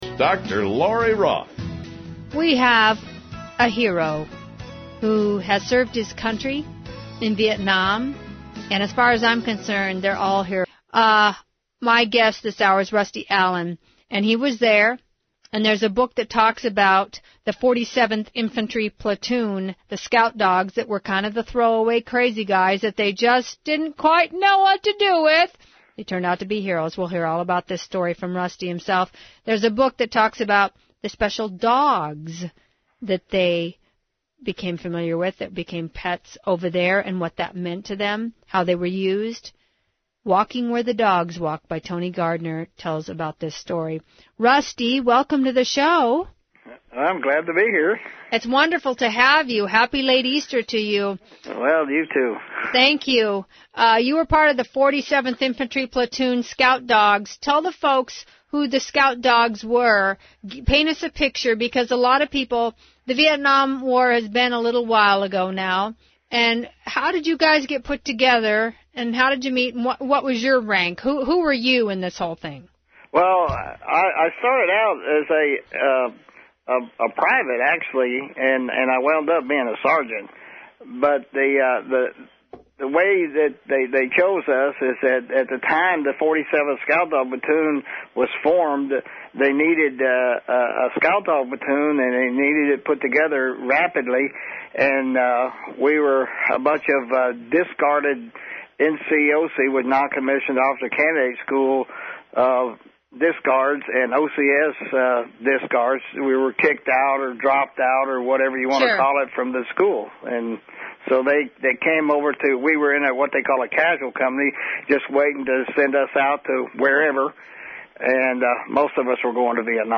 excerpted radio interview